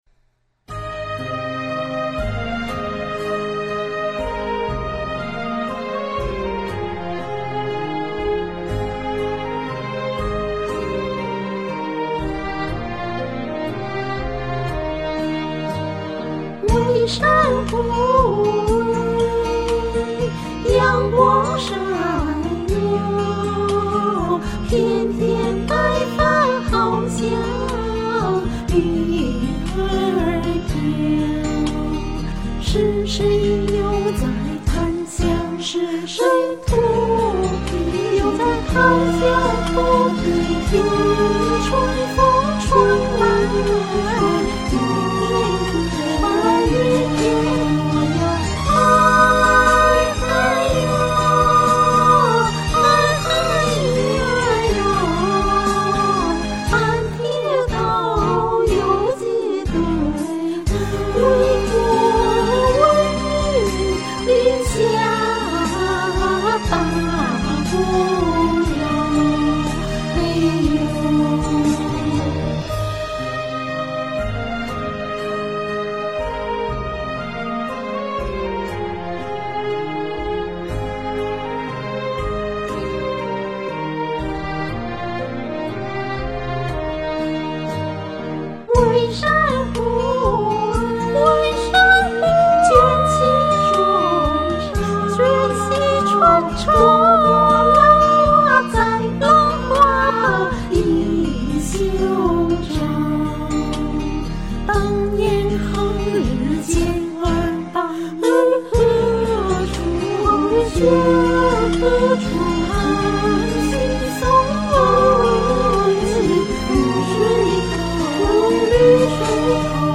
虽然伴奏不好，也没歌谱，不过，我还是在唱录这首歌时，为了琢磨如何唱，如何配和声，花了些心思。